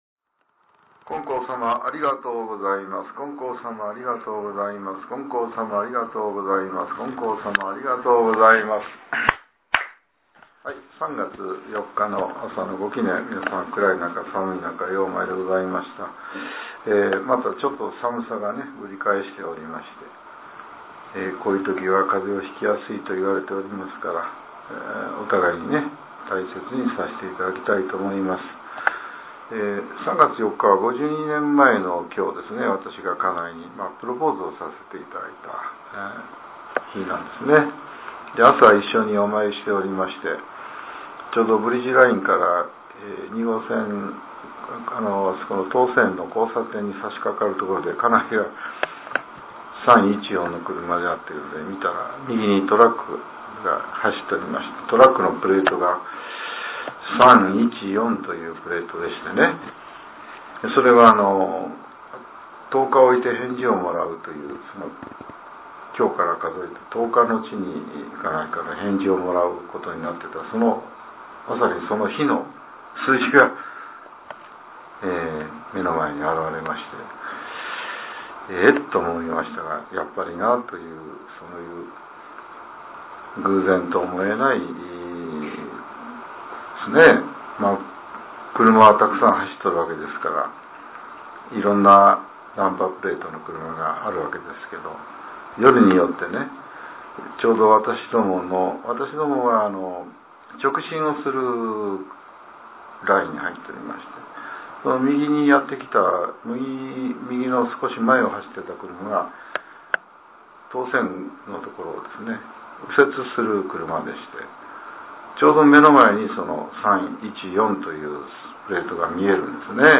令和７年３月４日（朝）のお話が、音声ブログとして更新されています。